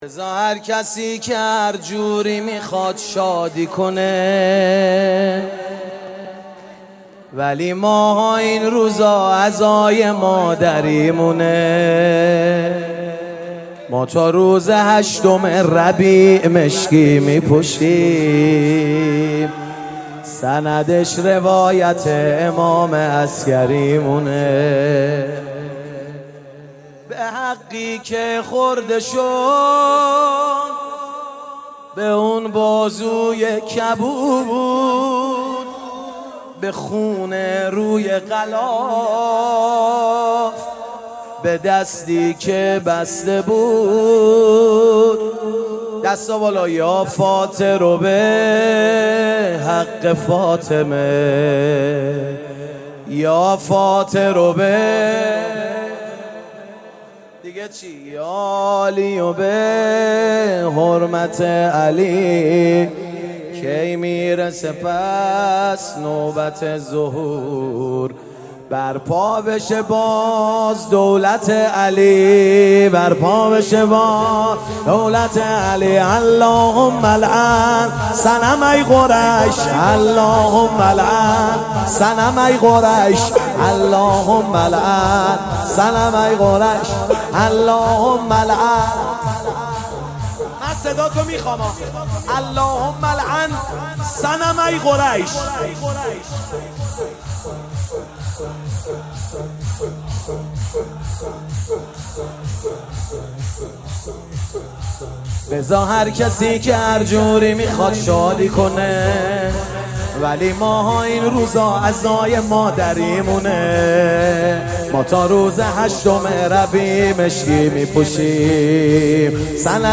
شور |فایل صوتی |بزار هرکسی که هرجوری میخواد شادی کنه - هیئت حیدریون اصفهان